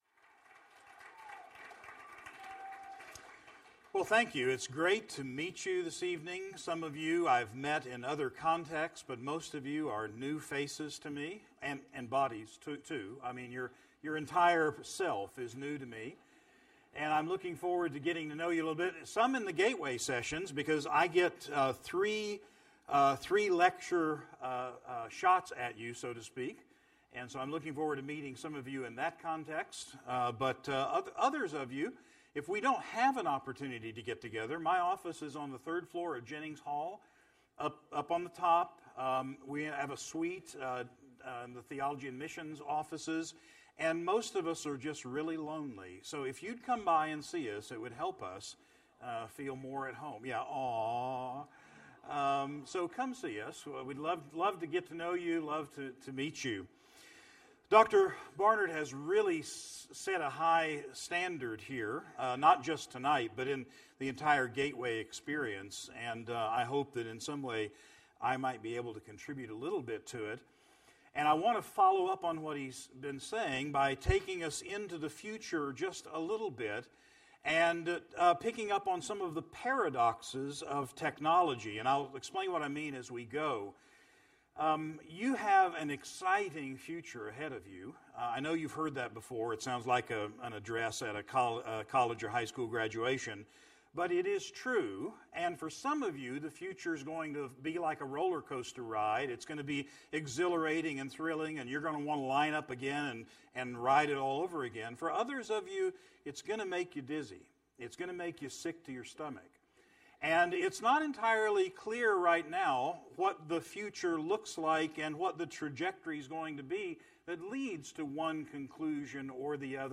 Address: The Paradoxes of Technology